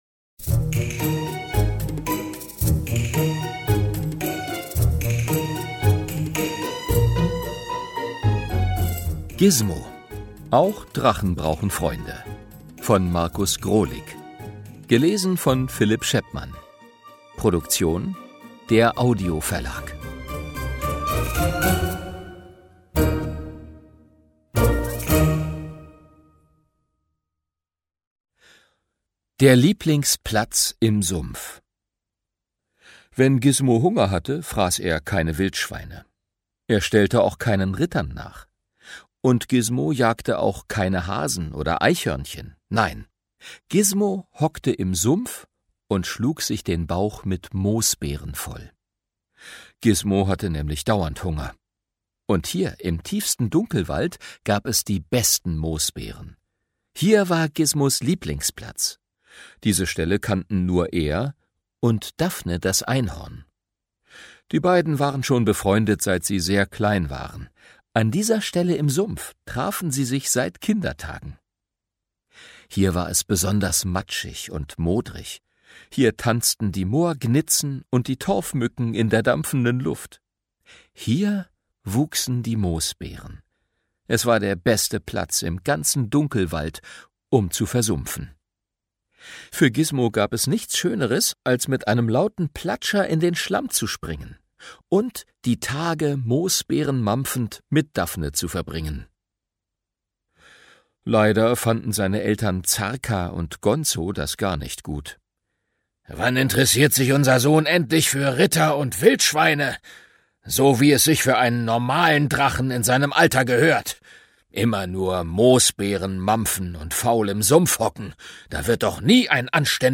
Gizmo – Auch Drachen brauchen Freunde Ungekürzte Lesung mit Musik